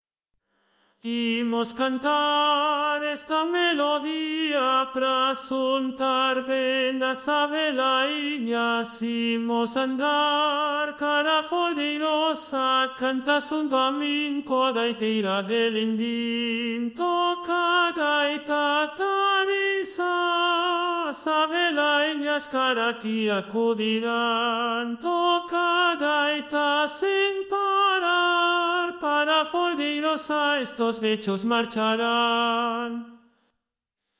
Tenor.mp3